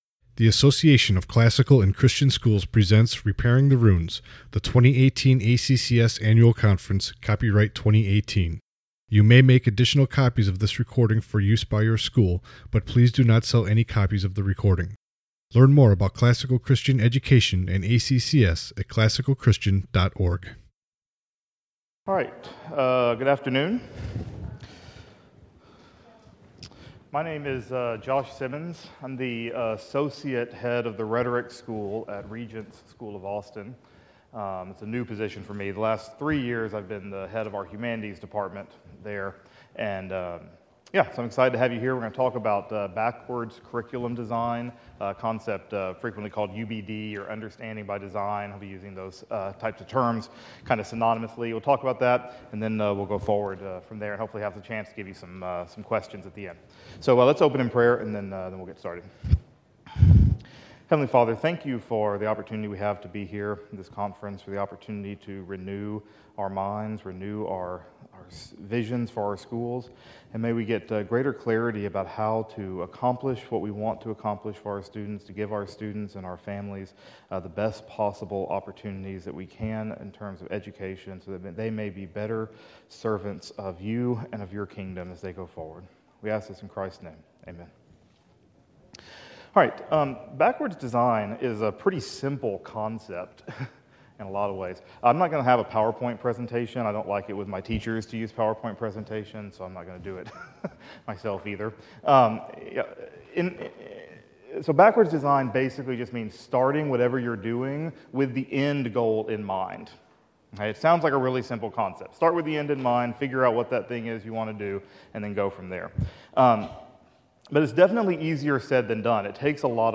2018 Leaders Day Talk | 46:35 | All Grade Levels, Curriculum Overviews, General Classroom